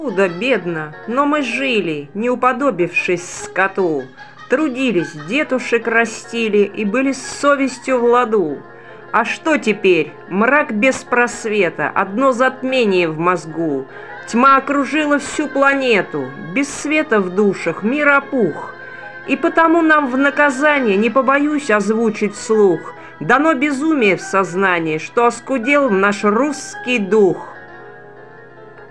Музыка классики